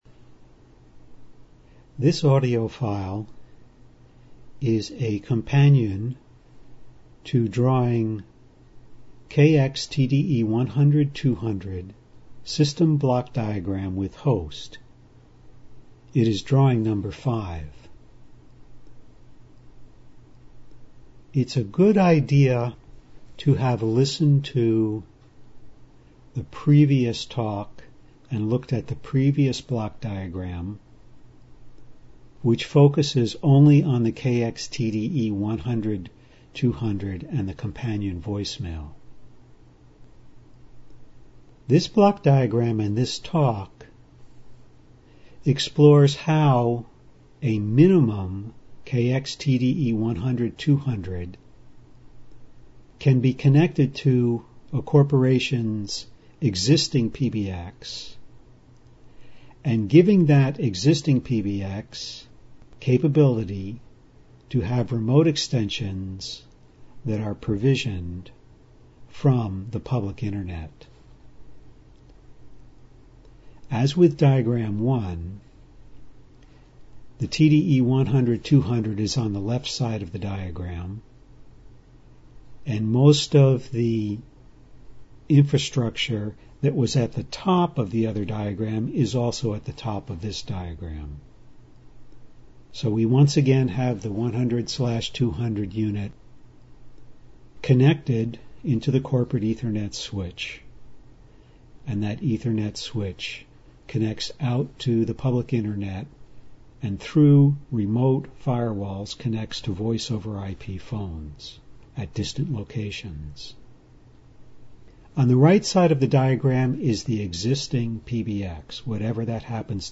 An Authorized Dealer For Panasonic KX-TDE Voice-Over-IP Telephone Systems Click Here For An Ad-Lib Informal Audio Description Track 1 Click Here For An Ad-Lib Informal Audio Description Track 2